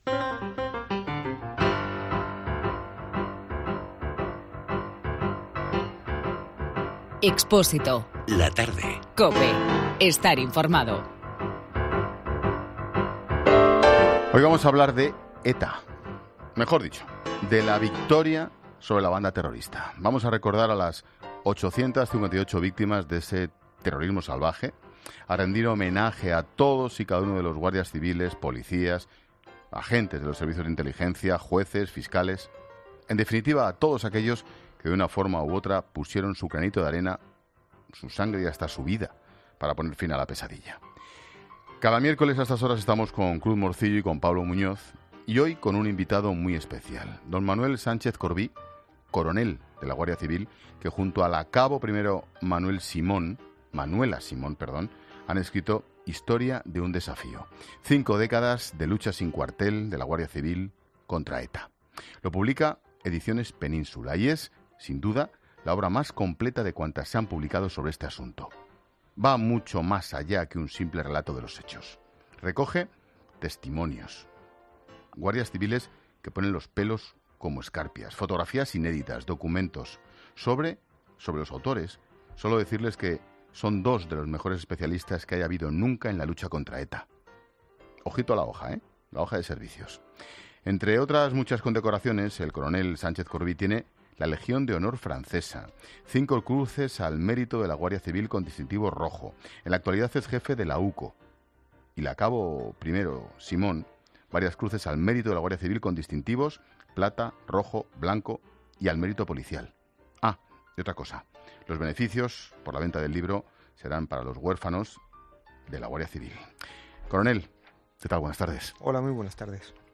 El coronel de la Guardia Civil y Jefe de la UCO, Manuel Sánchez Corbí, ha sido el invitado de este miércoles en 'Expediente Expósito'.